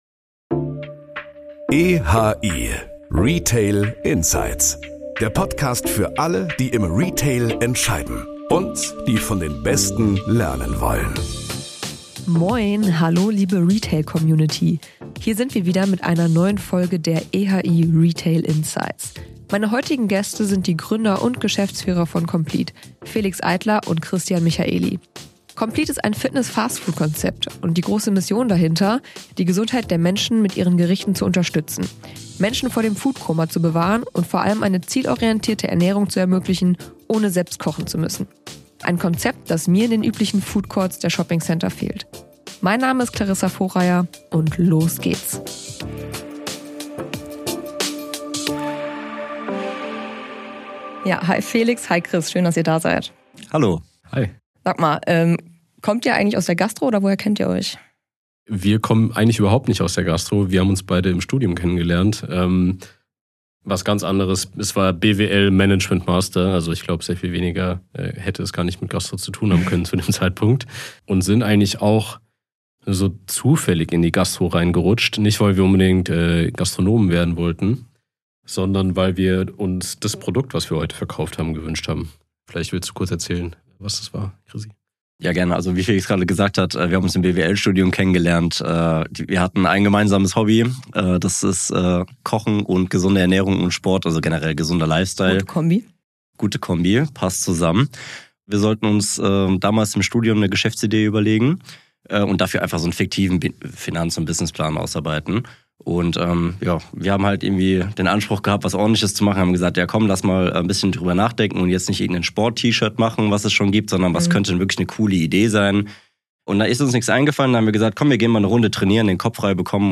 Eine lockere, ehrliche Folge mit praktischen Impulsen für Handel und Handelsgastronomie.